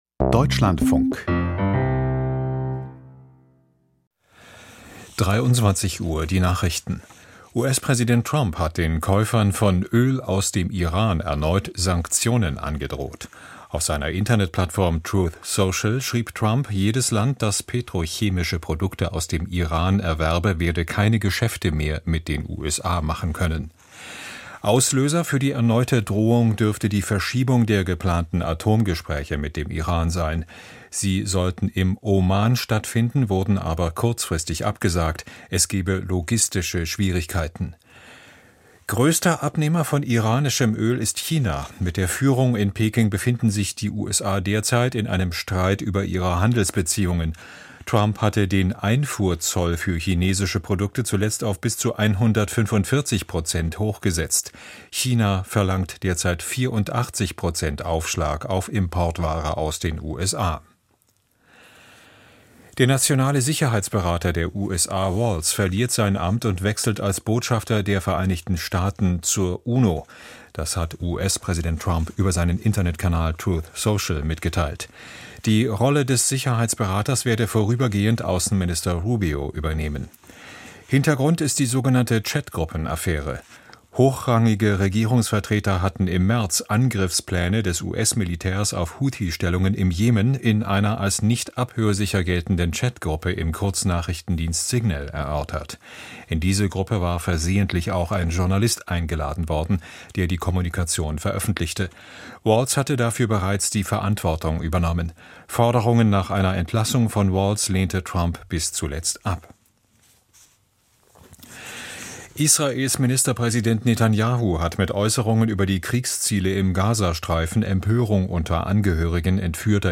Die Deutschlandfunk-Nachrichten vom 01.05.2025, 23:00 Uhr